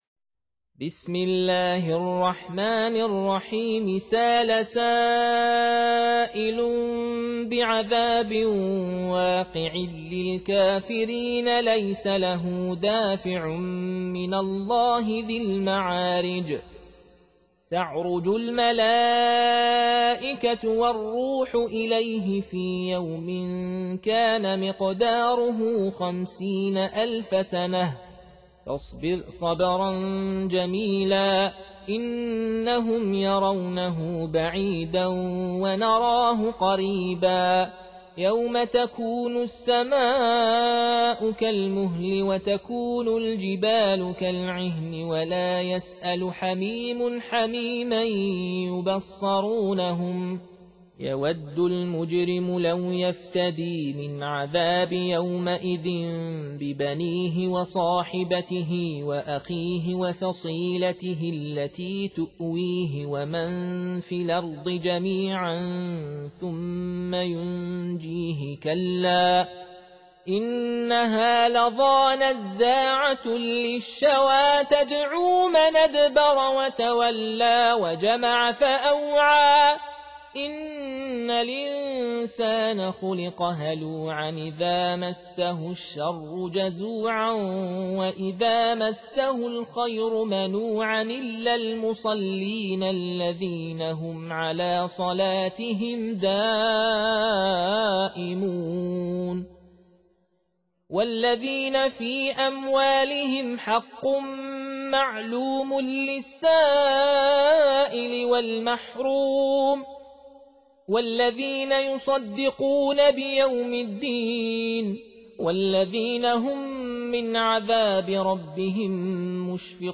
70. Surah Al-Ma'�rij سورة المعارج Audio Quran Tarteel Recitation
Surah Sequence تتابع السورة Download Surah حمّل السورة Reciting Murattalah Audio for 70.